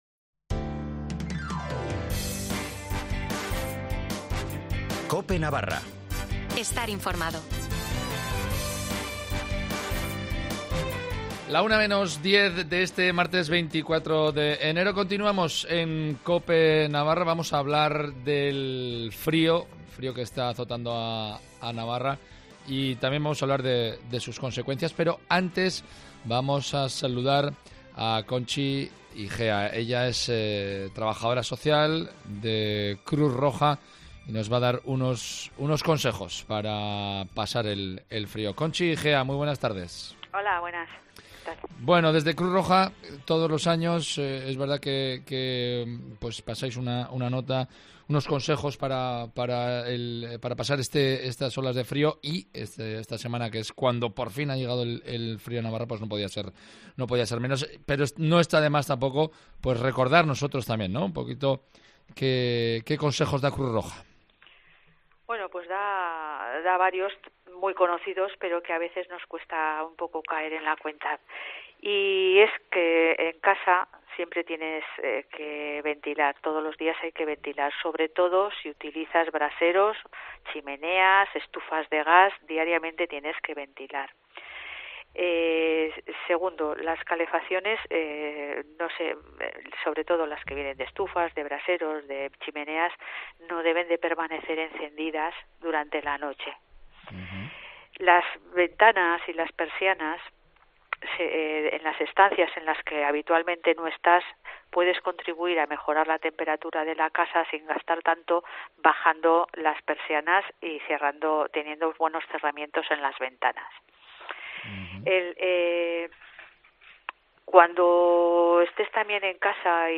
Y también hemos hablado con Carlos Anaut, alcalde de Isaba, puesto que hay más de medio metro de nueve acumulada en el pueblo.